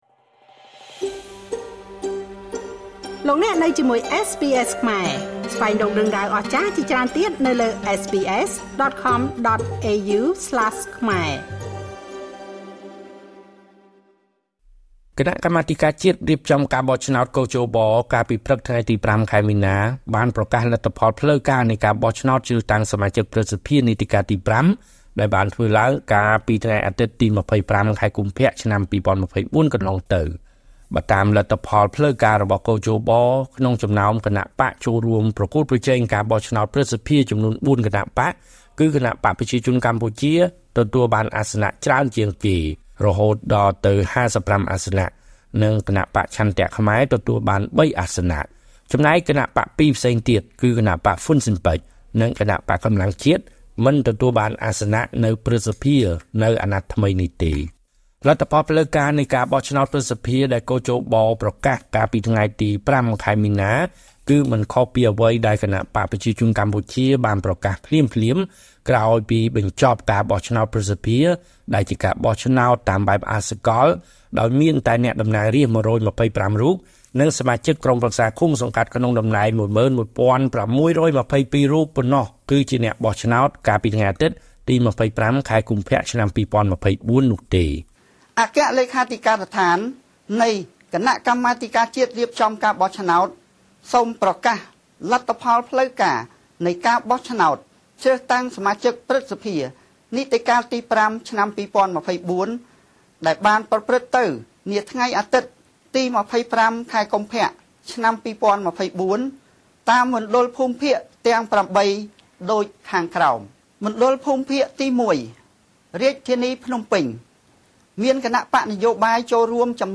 ( សំឡេងគ.ជ.ប )